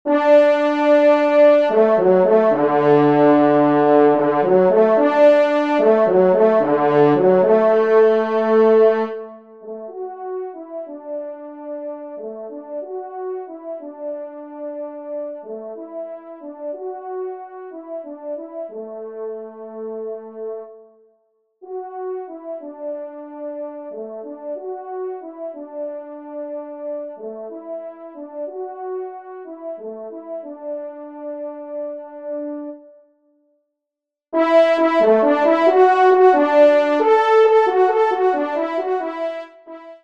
Pupitre 1°Trompe